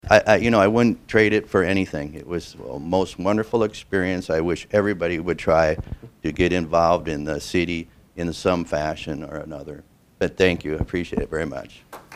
Three Clinton City Council members and the Mayor who were not re-elected in November were honored at the final meeting of the year.